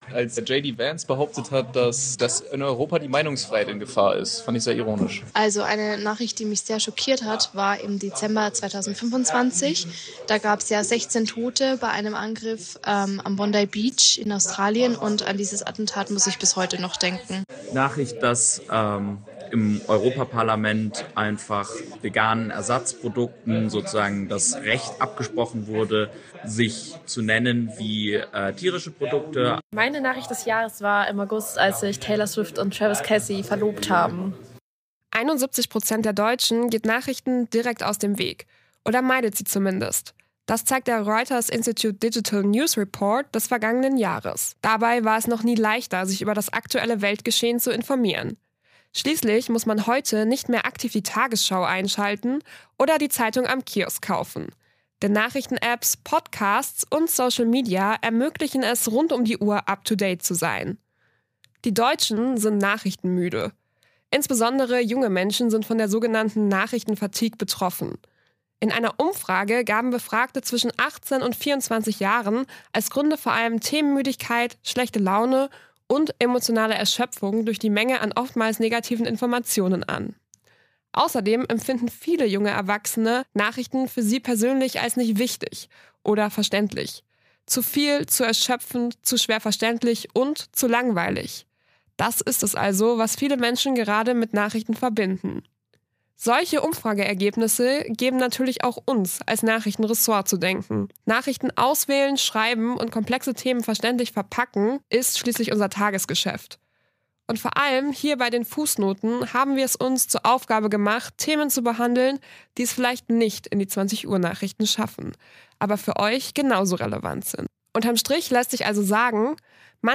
Dafür haben unsere Redakteur:innen mit Wissenschaftler:innen und Menschen von Vereinen gesprochen.